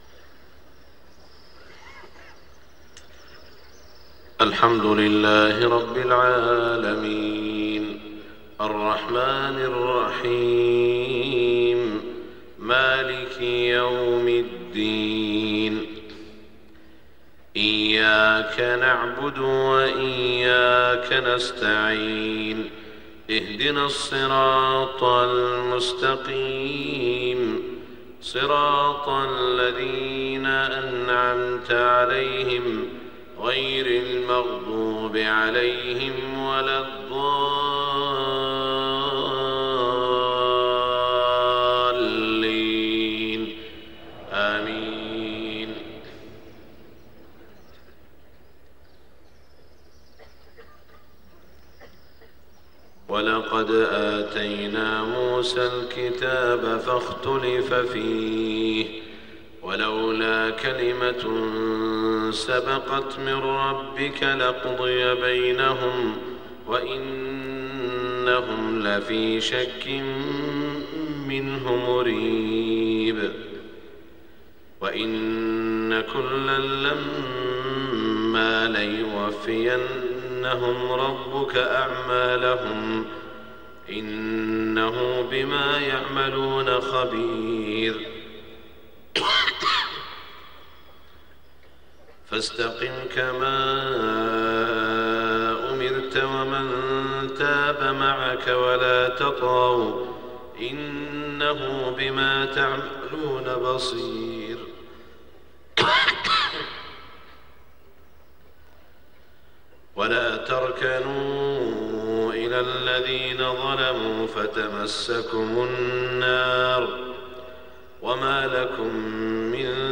صلاة الفجر 20 ذو الحجة 1427هـ من سورة هود > 1427 🕋 > الفروض - تلاوات الحرمين